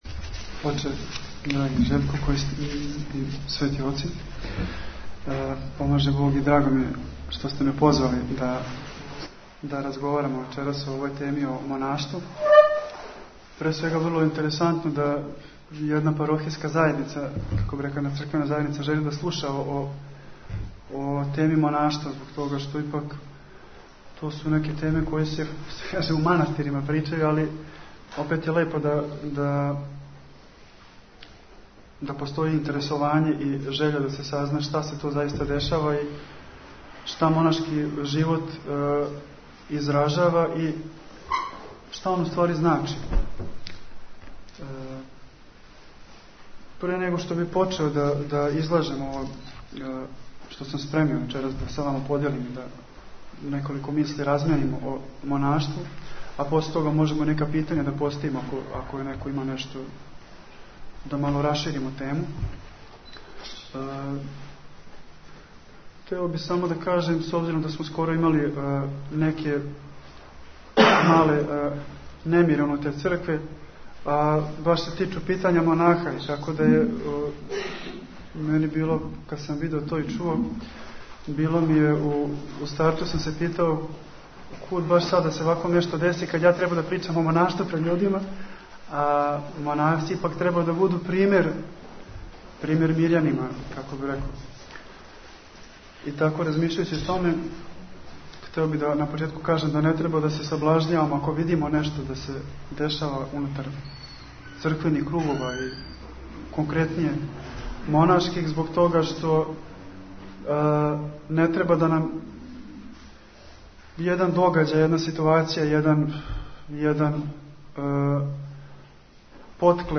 Звучни запис предавања
Футог